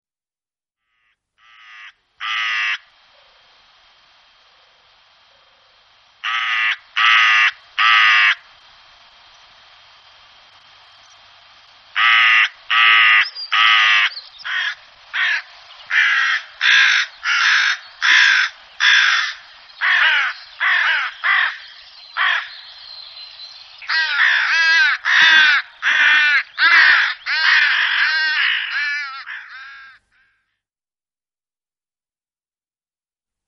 die genannten und abgebildeten Vögel sind im Park anzutreffen
Aaskrähe
Aaskrahe.MP3